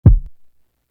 Dub Kick.wav